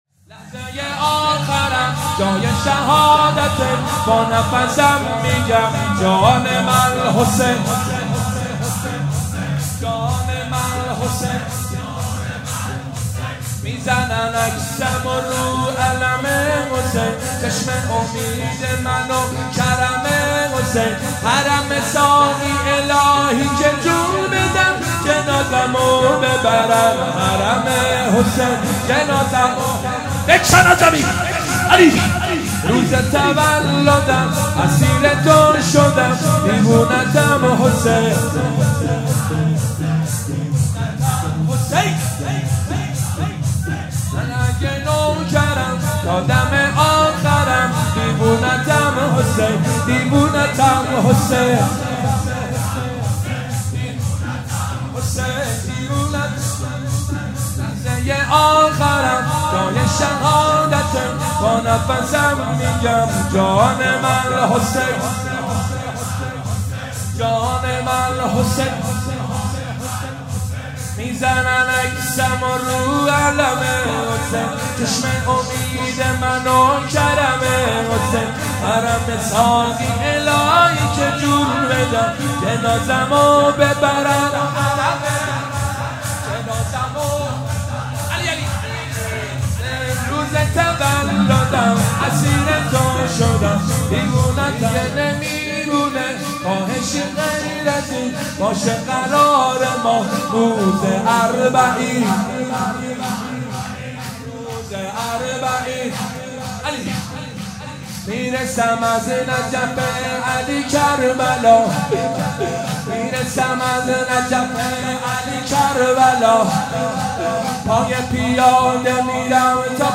ایام فاطمیه 1441